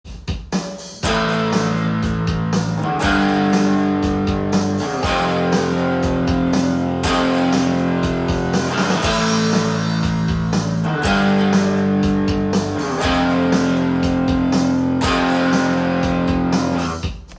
コード進行は、ロック系の曲でよく見かける
パワーコードの基本コード進行
pawa.chord_.m4a